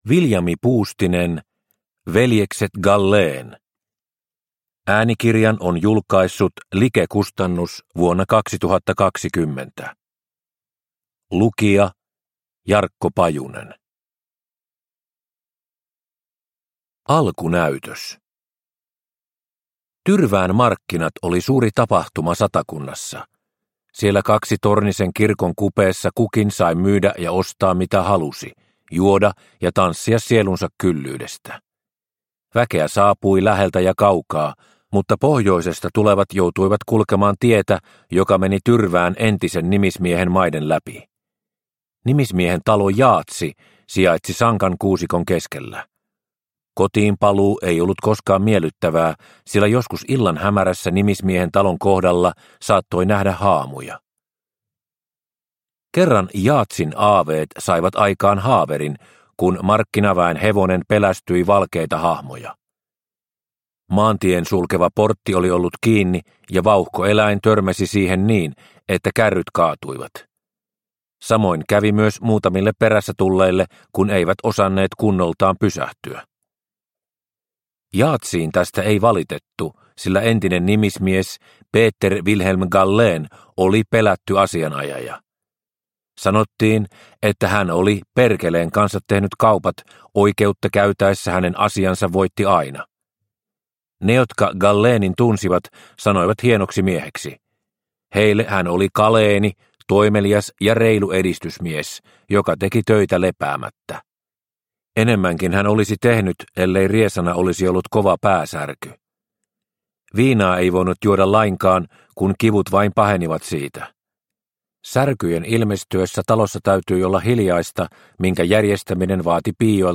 Veljekset Gallén – Ljudbok – Laddas ner